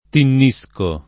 tinnisco [ tinn &S ko ]